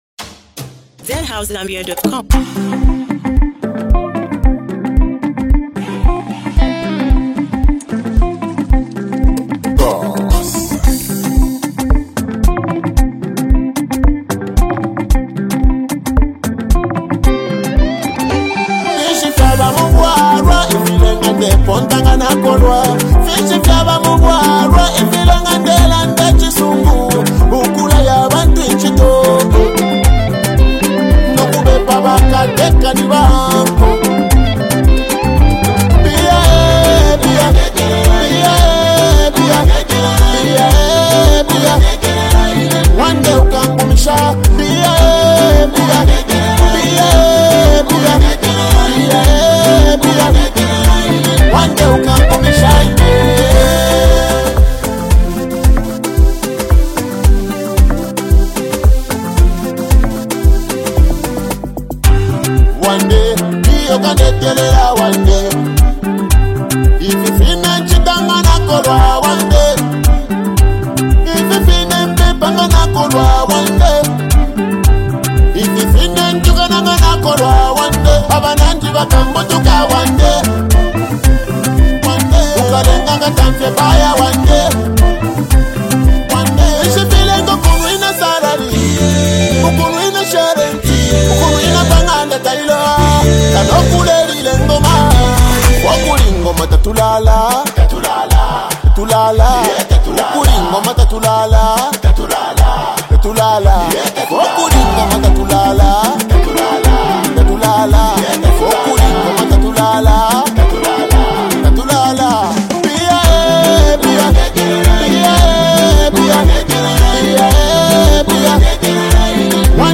a December banger